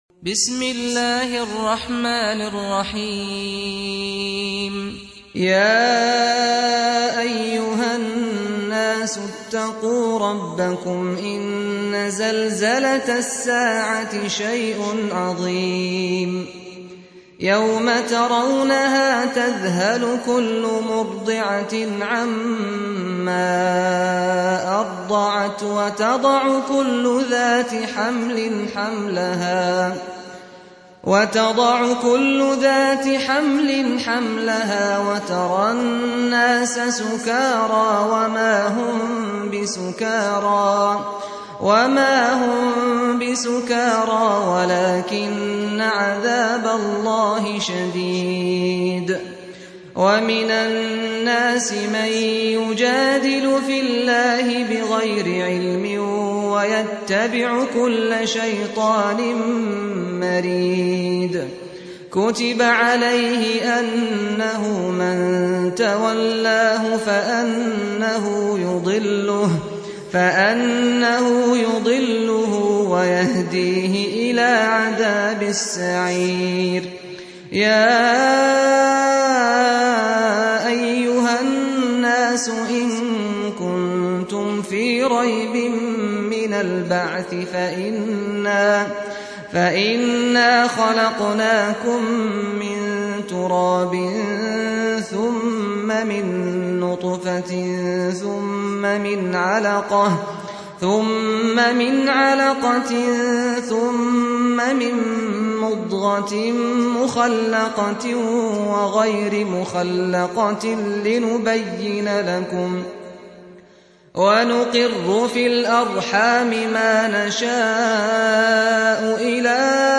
22. Surah Al-Hajj سورة الحج Audio Quran Tarteel Recitation
Surah Repeating تكرار السورة Download Surah حمّل السورة Reciting Murattalah Audio for 22.